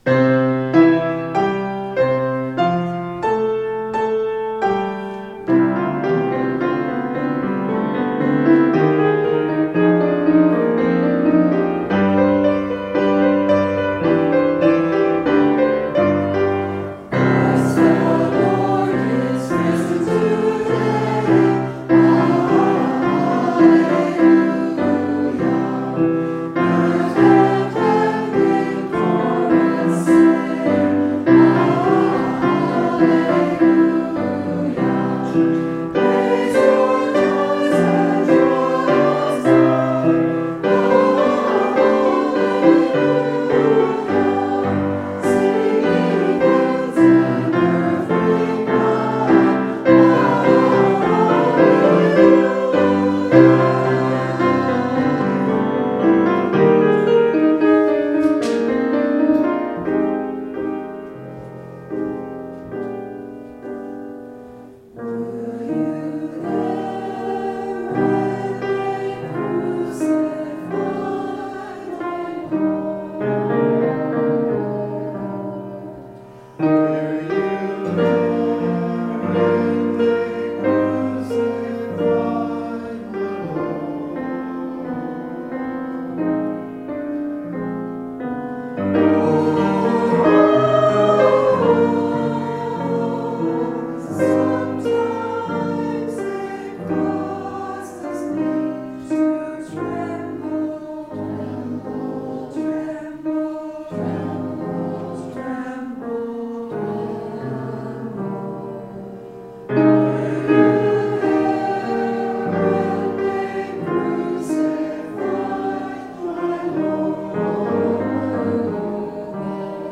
A Medley of Easter Music